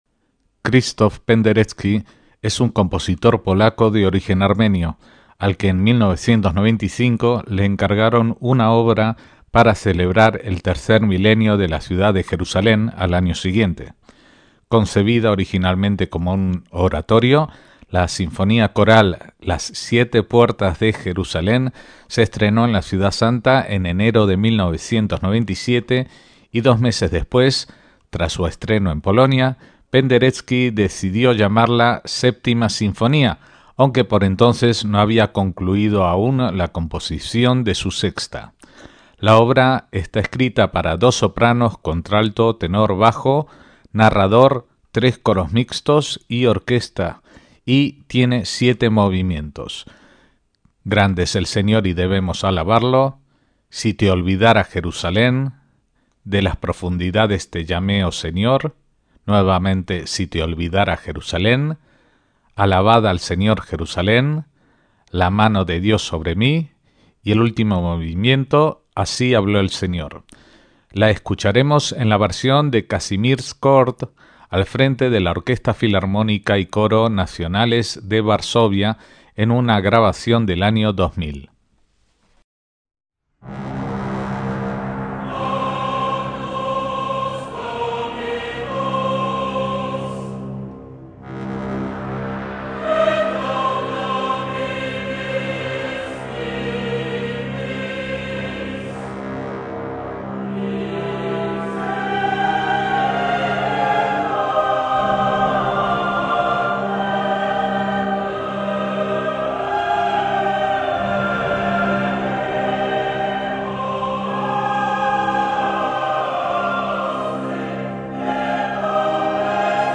MÚSICA CLÁSICA